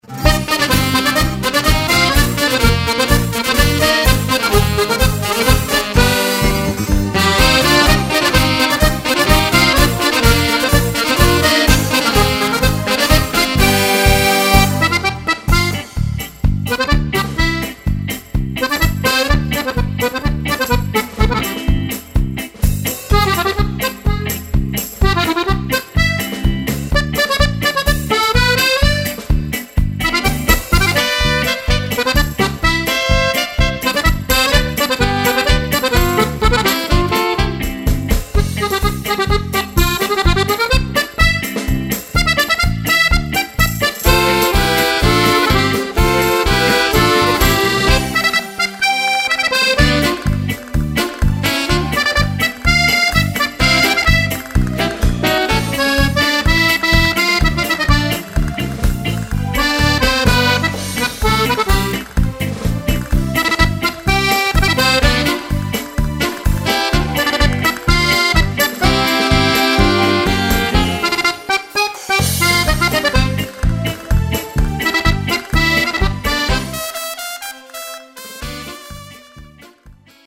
Paso doble
Fisarmonica